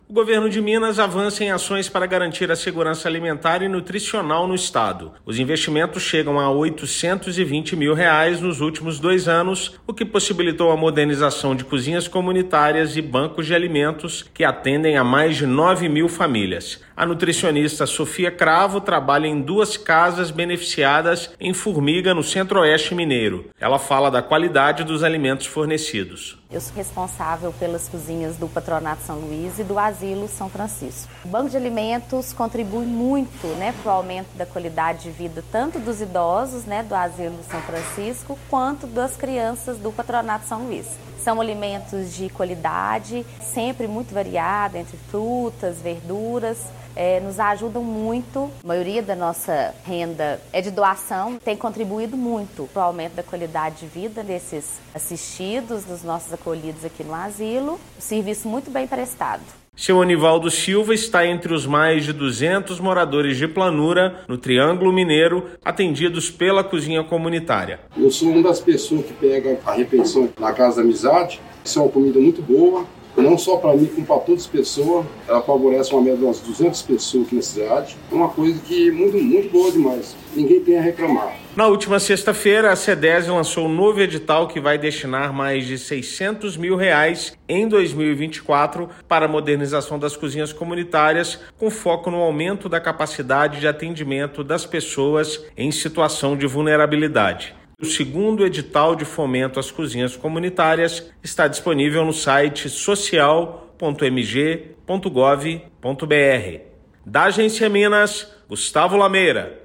Bancos de alimentos e cozinhas comunitárias ajudam mineiros em situação de vulnerabilidade social; novo edital vai elevar o investimento estadual a R$ 1,4 milhão. Ouça matéria de rádio.